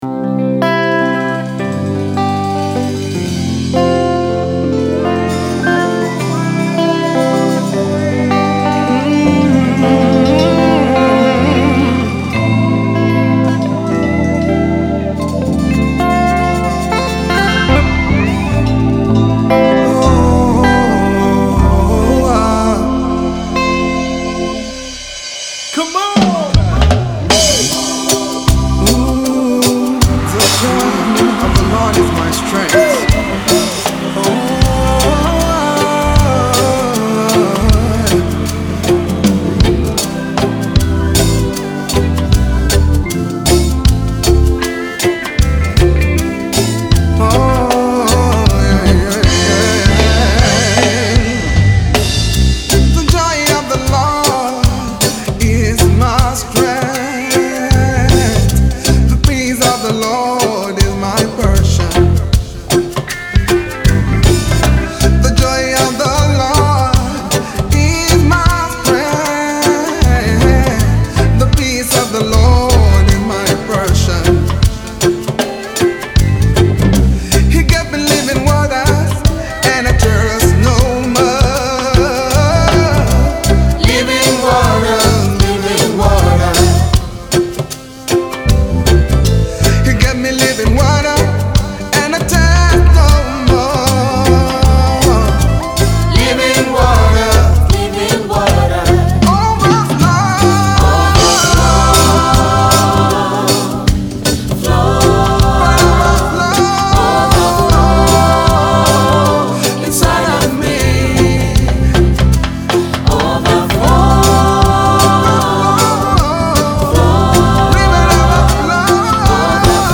Nigerian gospel singer
uplifting reggae song